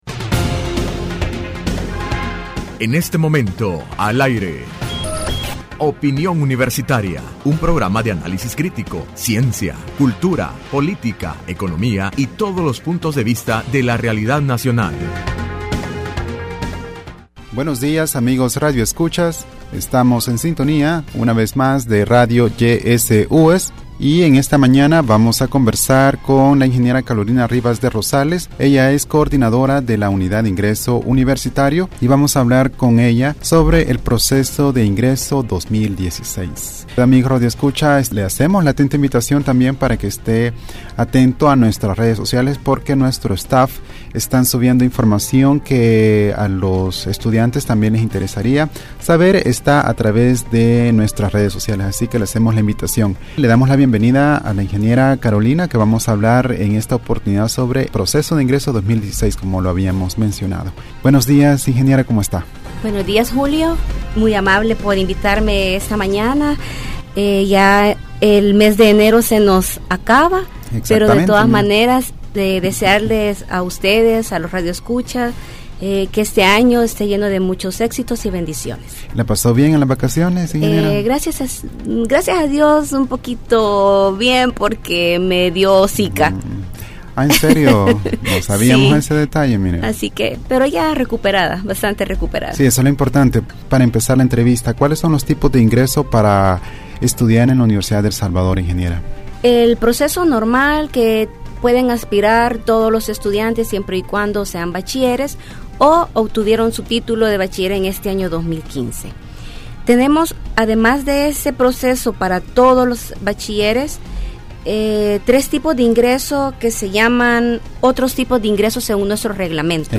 Entrevista Opinión Universitaria(28 de enero 2016): Proceso nuevo ingreso 2016.Resultados